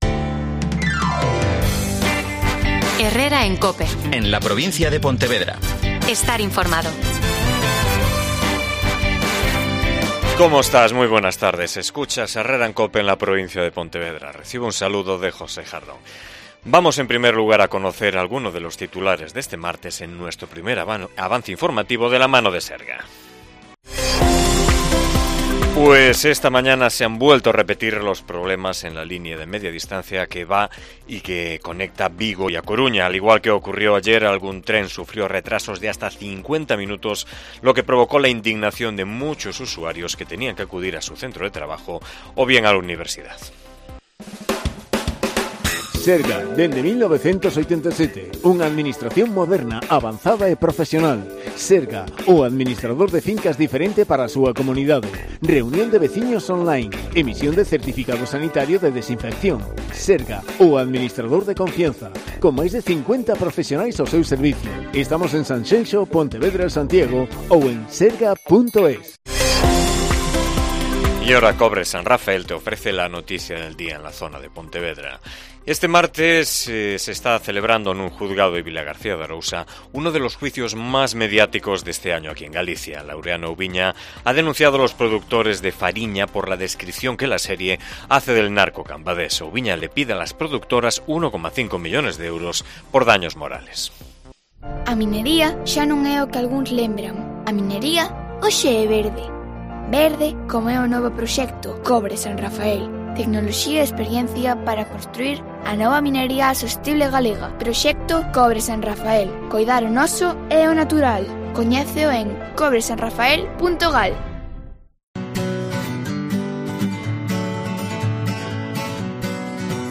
AUDIO: Magazine provincial